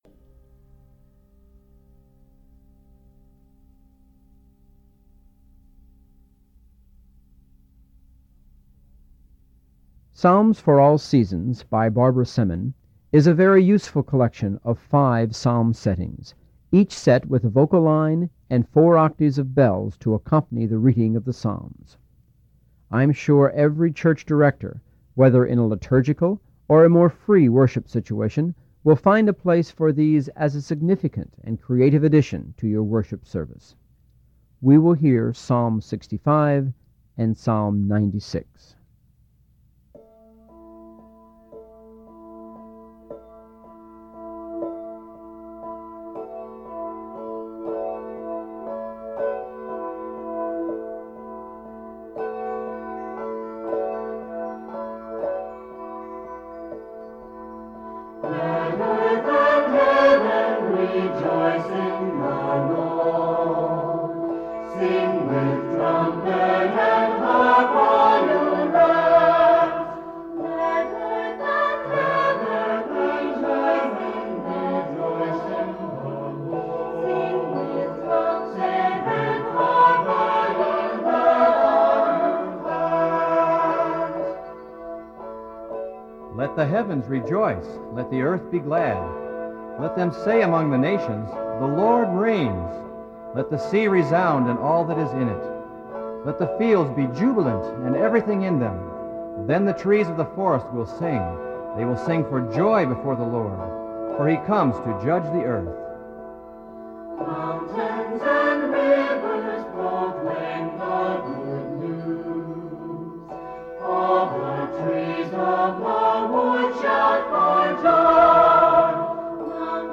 Bell parts develop patterns such as in Orff music.